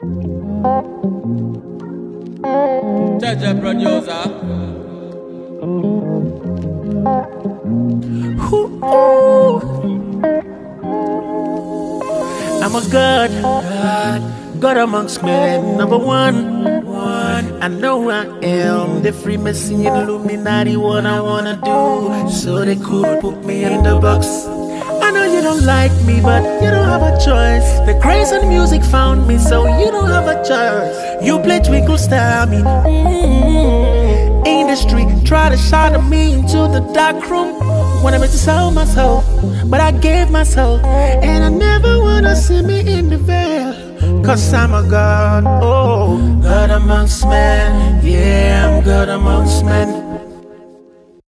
is a powerful and uplifting track